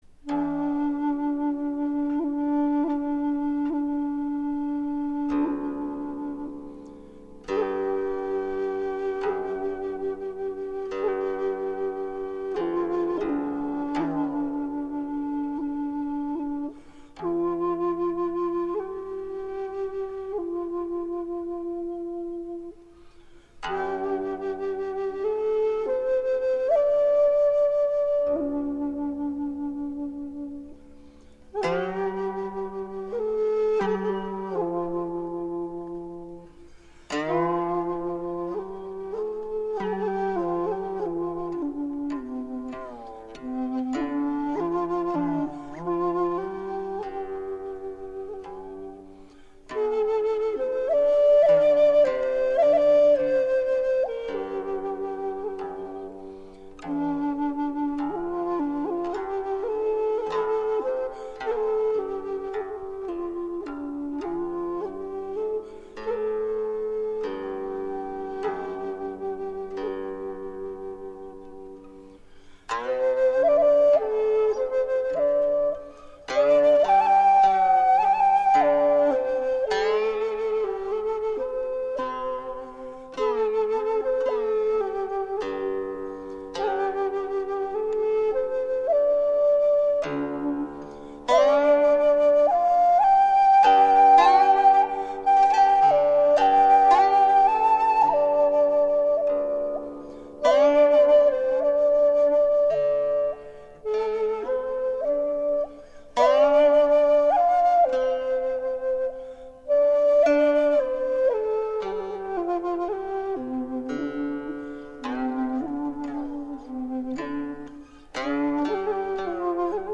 演出：古琴